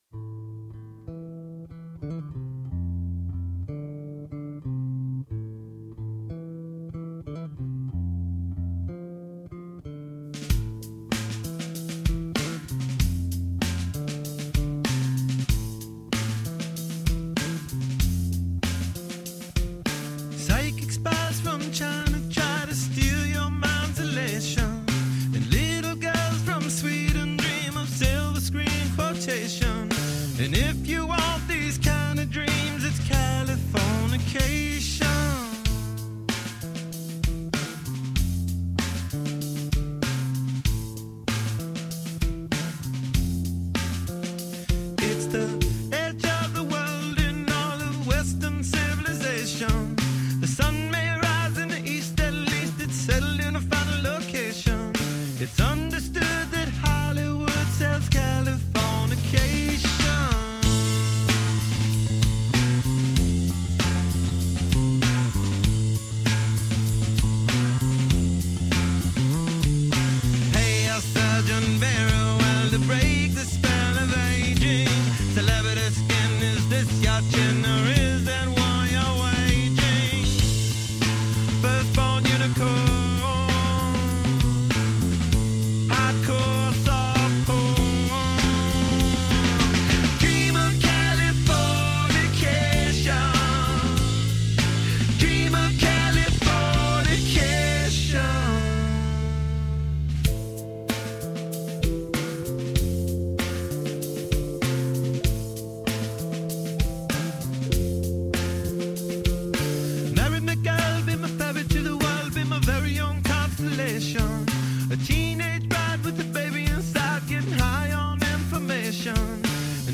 Tempat Download Backing Track